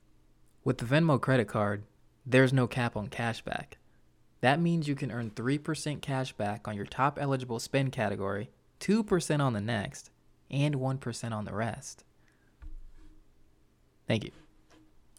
25 year old American male. average American voice with sarcasm and lighthearted tone
Venmo Commercial
Midwest American, northern American